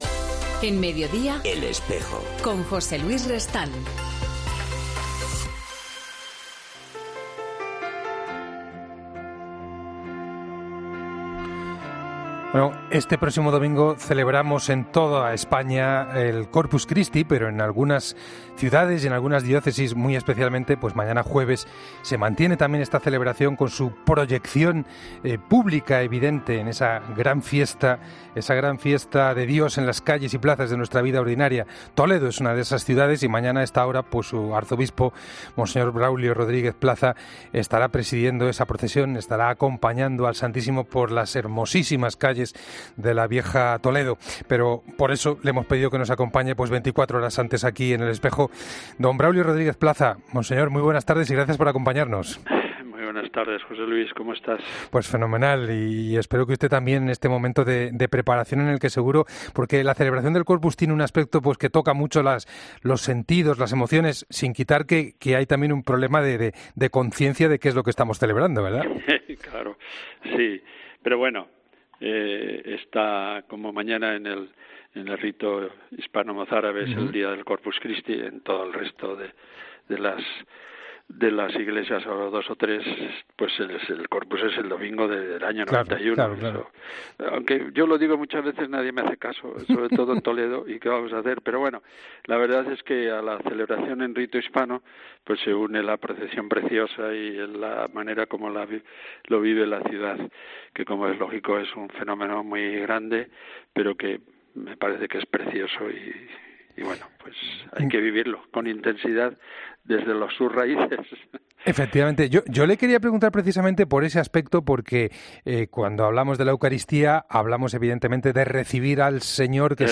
Y precisamente, en 'El Espejo' hemos hablado con monseñor Braulio Rodríguez Plaza, arzobispo de Toledo .